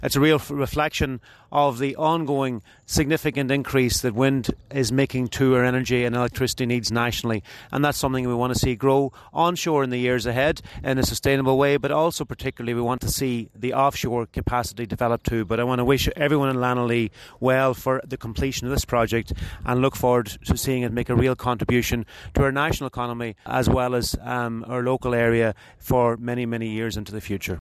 Minister McConalogue was speaking after he officially opened the seven turbine Lenalea Wind Farm, developed by SSE Renewables and FuturEnergy Ireland, close to Cark, 10 km south west of Letterkenny.
Minister McConalogue says it’s a significant development………….